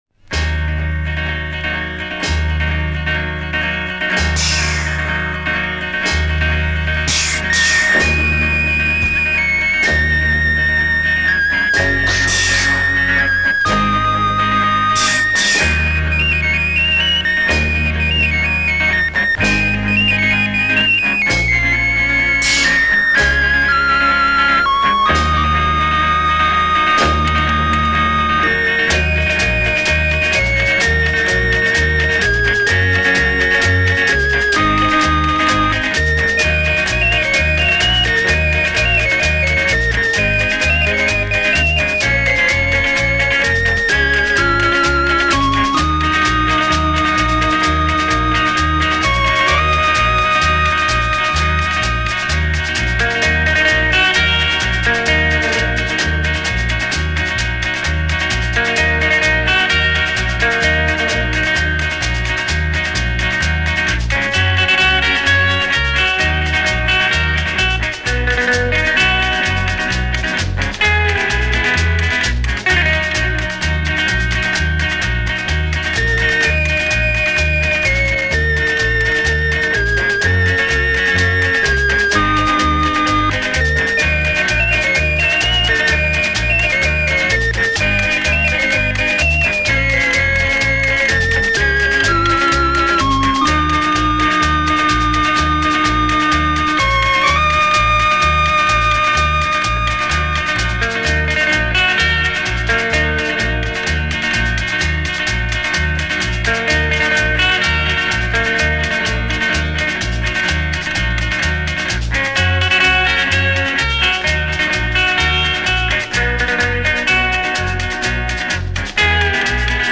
Lead Guitar
Rhythm Guitar
Bass Guitar
Electronic Organ
Drums
Vocals